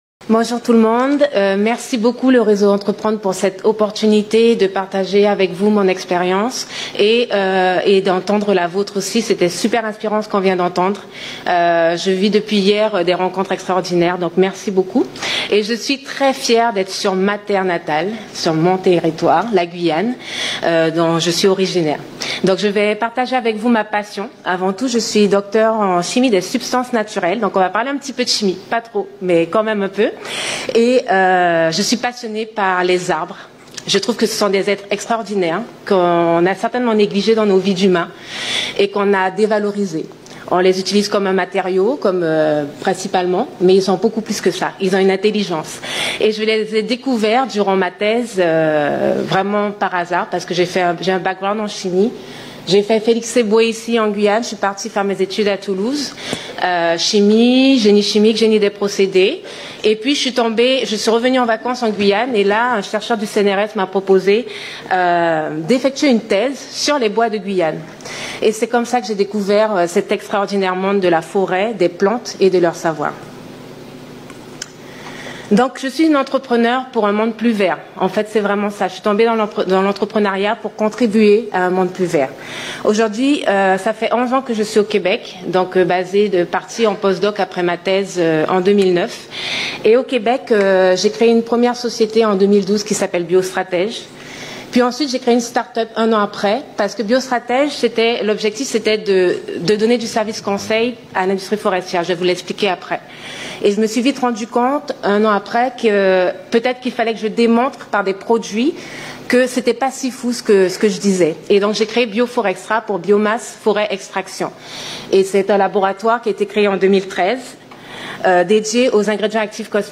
Conférence Interdom à la biennale à la salle jupiter au centre spatial à Kourou.